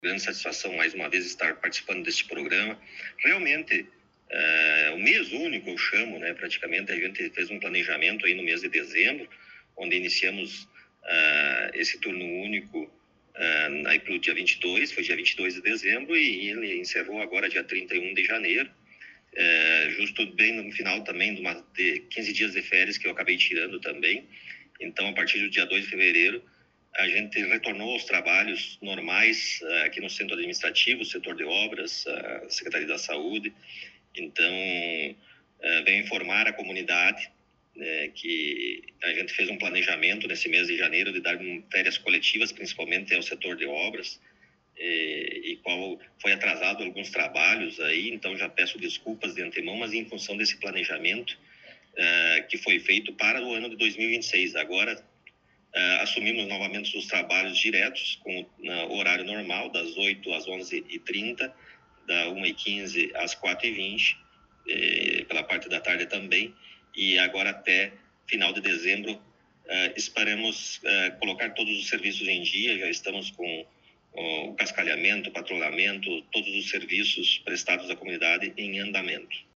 Entrevista com o Prefeito Rodrigo Sartori: IPTU 2026 e Obras no Município
Na última semana, em um encontro no gabinete da prefeitura, tivemos a oportunidade de entrevistar o prefeito Rodrigo Sartori, logo após seu retorno das férias.